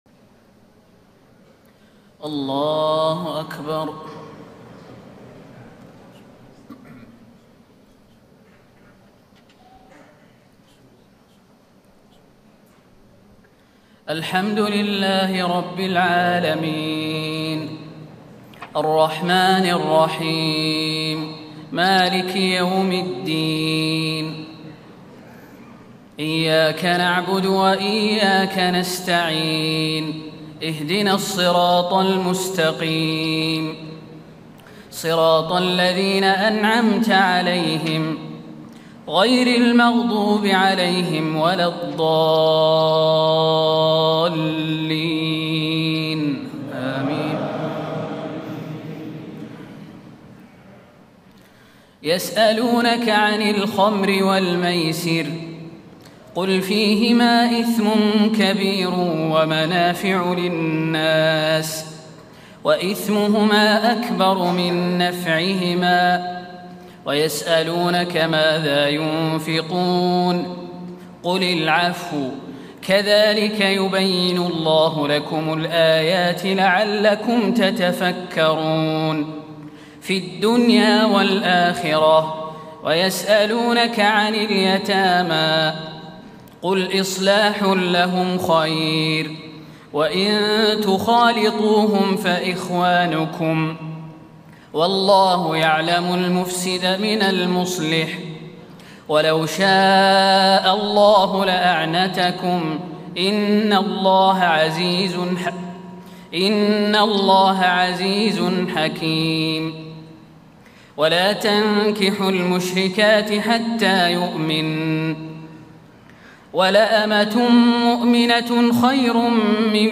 تهجد ليلة 22 رمضان 1436هـ من سورة البقرة (219-253) Tahajjud 22 st night Ramadan 1436H from Surah Al-Baqara > تراويح الحرم النبوي عام 1436 🕌 > التراويح - تلاوات الحرمين